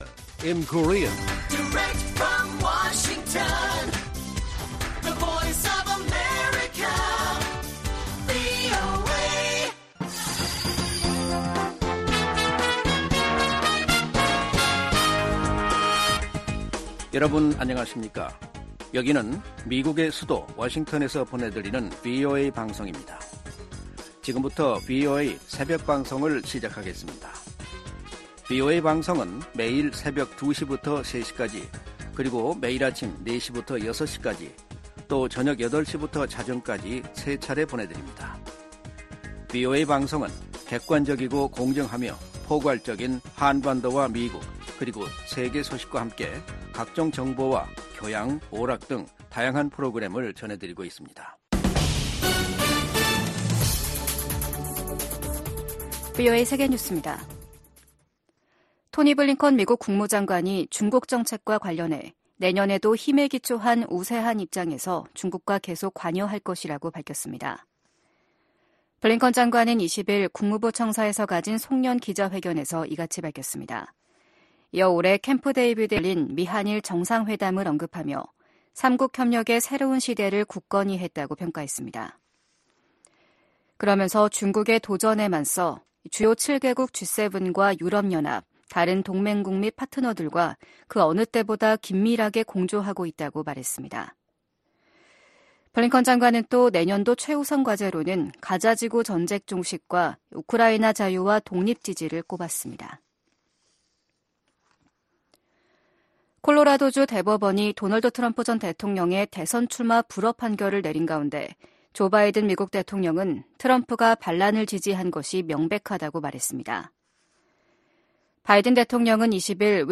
VOA 한국어 '출발 뉴스 쇼', 2023년 12월 22일 방송입니다 유엔 총회가 북한의 조직적이고 광범위한 인권 침해를 규탄하는 결의안을 19년 연속 채택했습니다. 김정은 북한 국무위원장은 어디 있는 적이든 핵 도발에는 핵으로 맞서겠다고 위협했습니다. 토니 블링컨 미 국무장관이 내년에도 중국에 대한 견제와 관여 전략을 병행하겠다는 계획을 밝혔습니다.